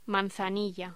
Locución: Manzanilla
voz